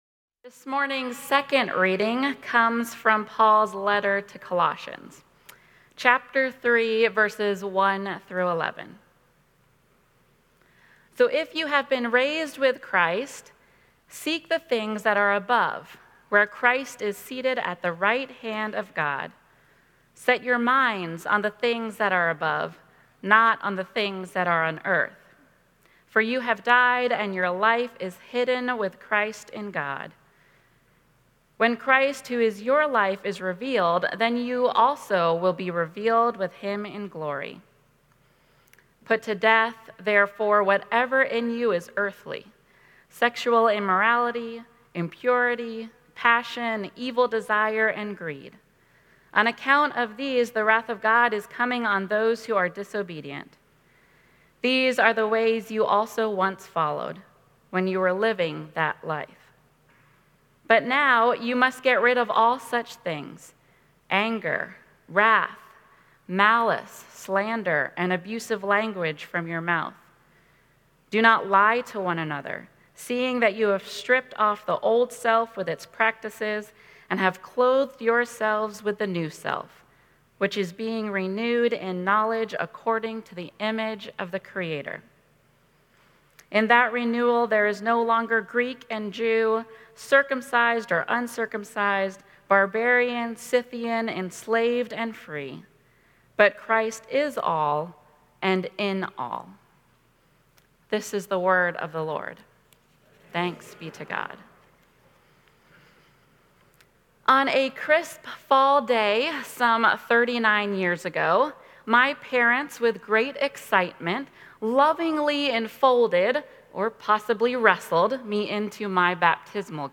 Audio Sermons details